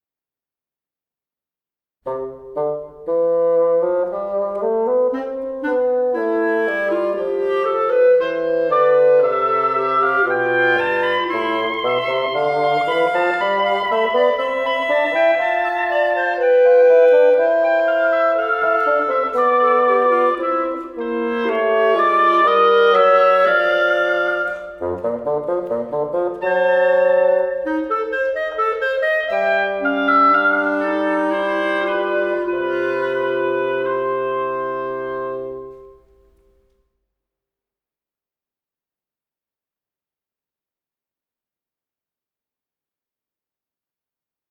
ソルフェージュ 聴音: コンサートホール・ヴァージョン(木管合奏)
コンサートホール・ヴァージョン(木管合奏)